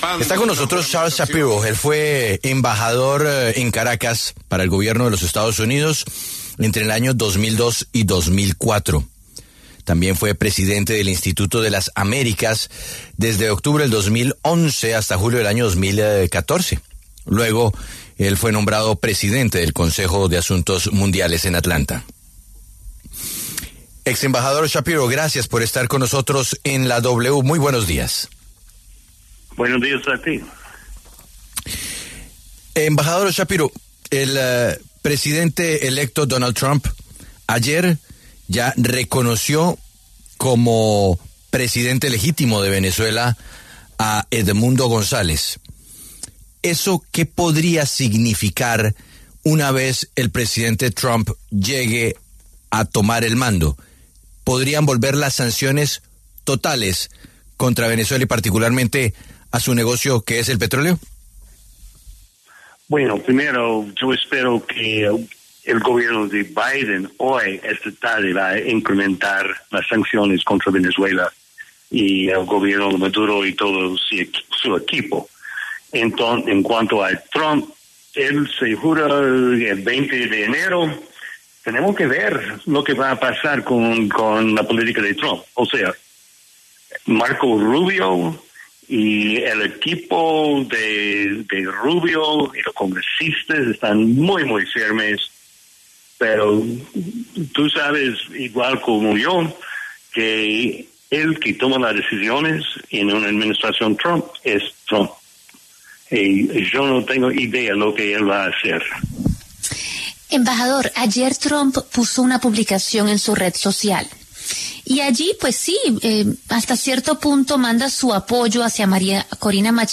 Charles Shapiro, diplomático estadounidense, exembajador en Venezuela y expresidente del Instituto de las Américas, habló con La W a propósito de la posesión de Nicolás Maduro como presidente de Venezuela y la de Donald Trump como el mandatario de Estados Unidos.